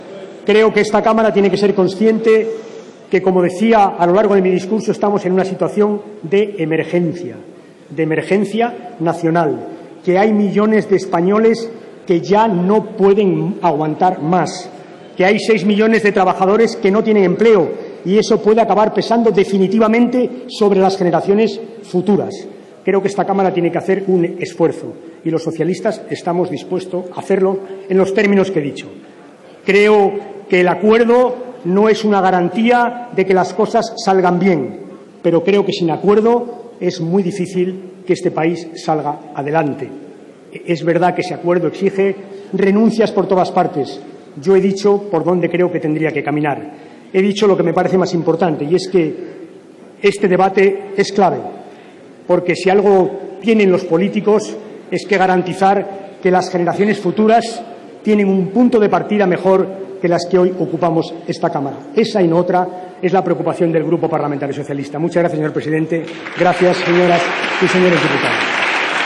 Alfredo P. Rubalcaba. Debate del Estado de la Nación 20/02/2013